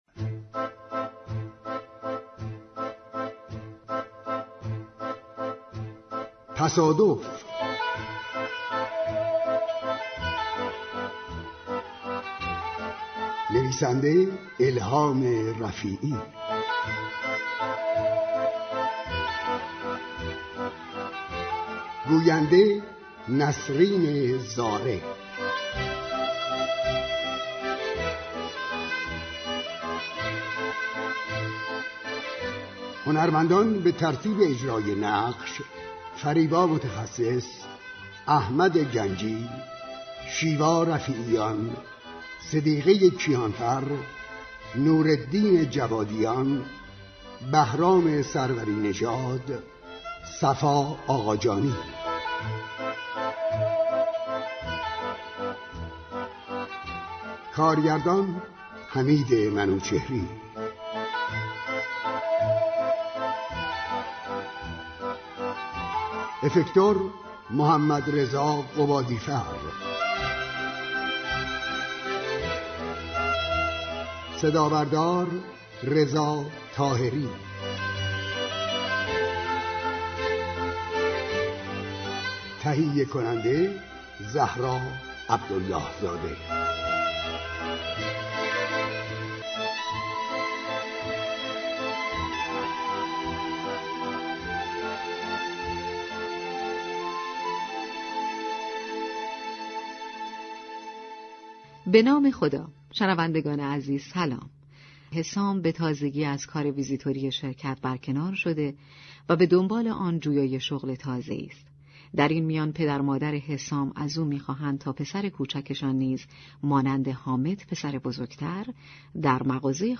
دوشنبه ، اول مرداد ، شنونده سریال جدید رادیویی